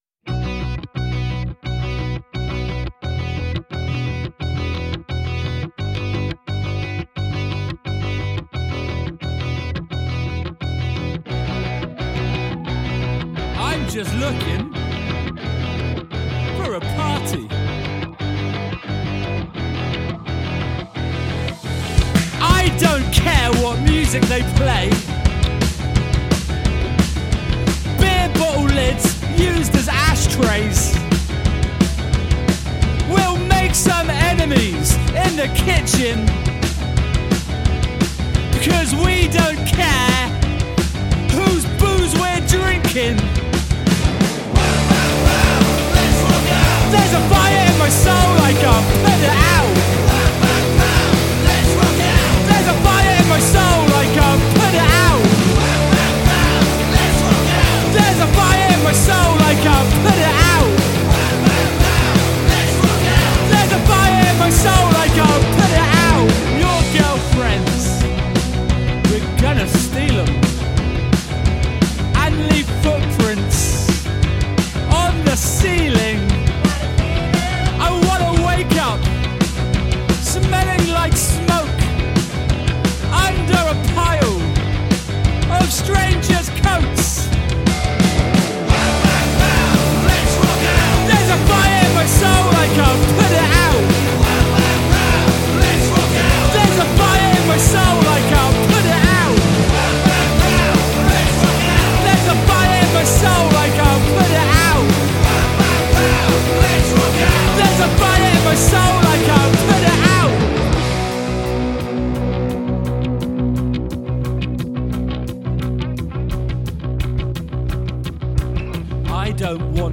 indie-rock